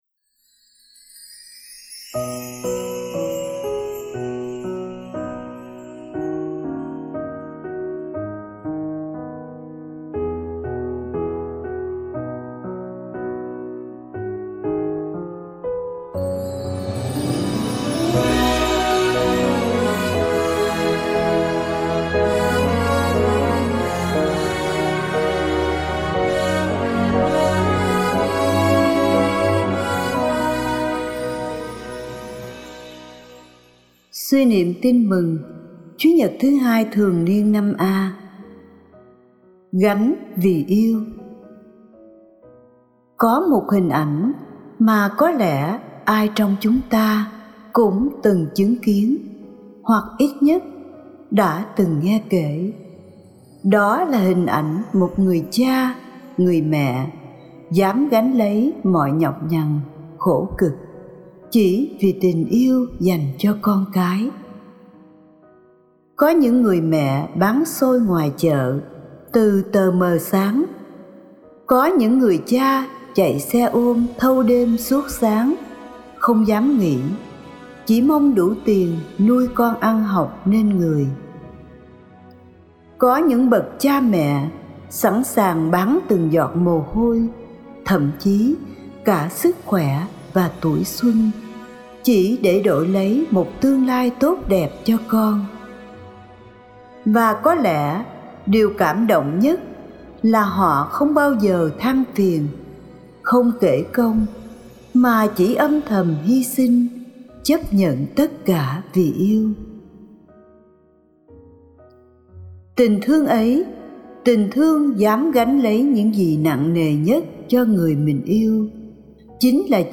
Gánh vì yêu (Bài giảng lễ Chúa nhật 2 thường niên A - 2026)